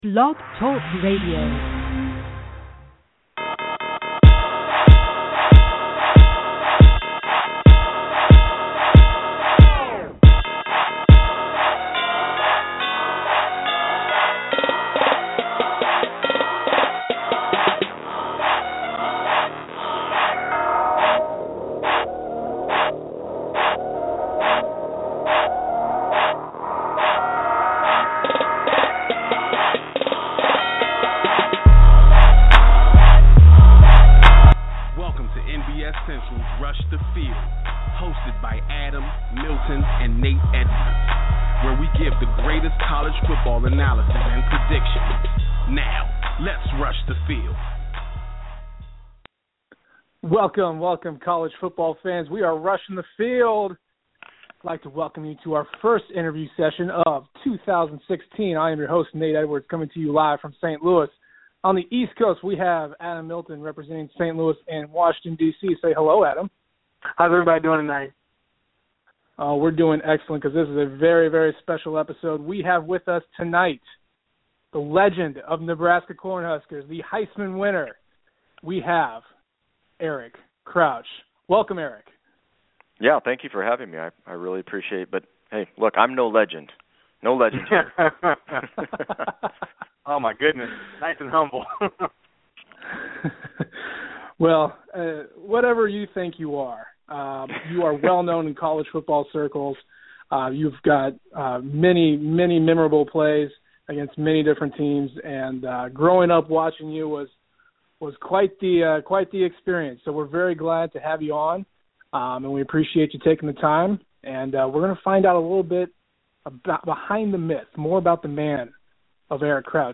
Exclusive Interview with Eric Crouch